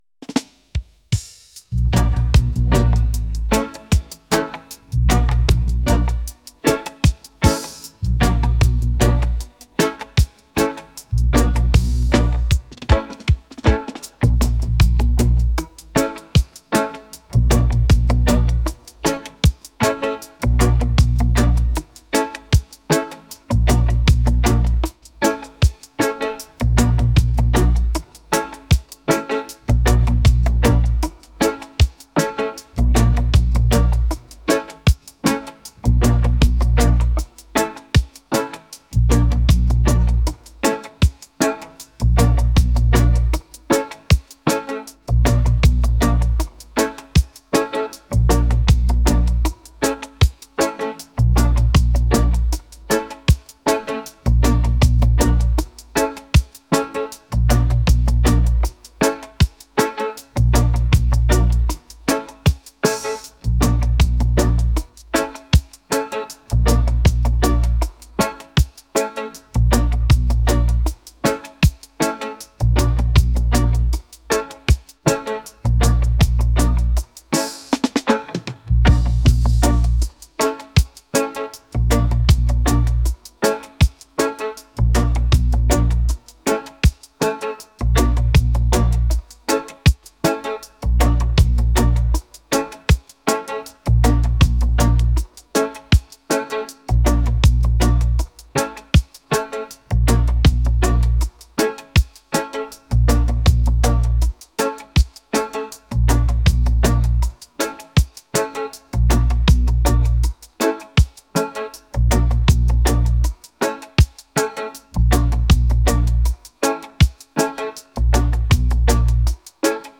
reggae | romantic